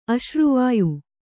♪ aśruvāyu